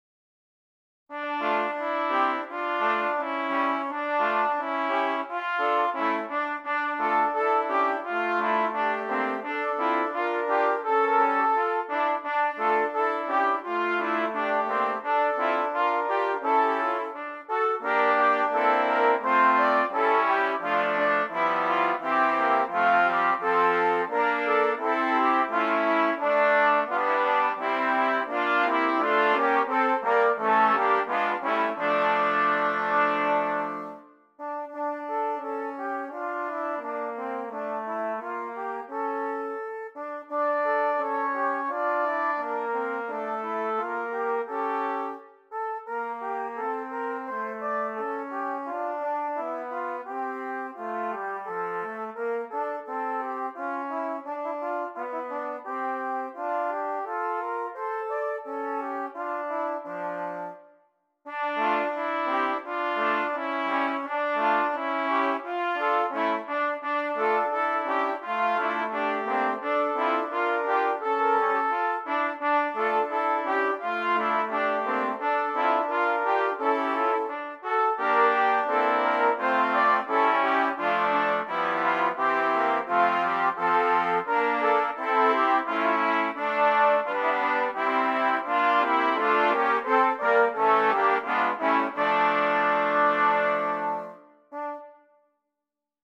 6 Trumpets
Traditional Carol